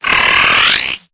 Cri_0833_EB.ogg